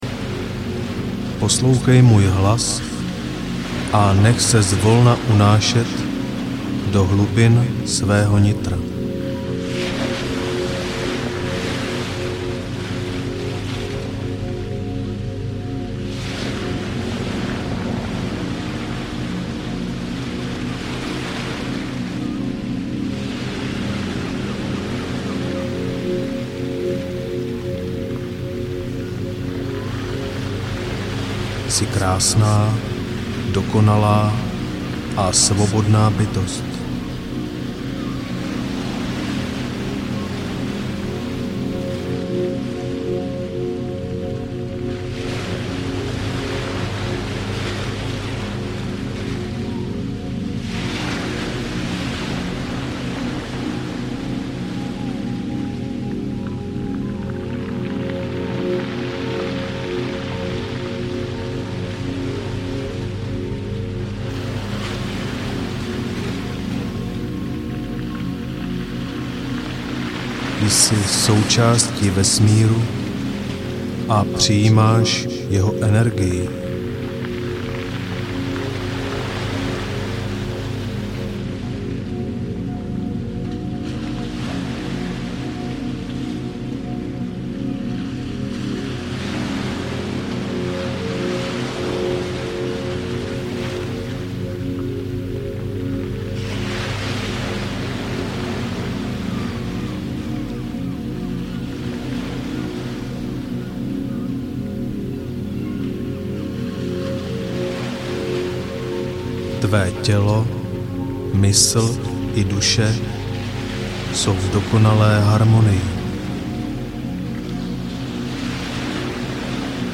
Pozitivní programování mysli audiokniha
Ukázka z knihy
Ponořte se do příjemné hudby a nechte se unášet na vlnách pohody.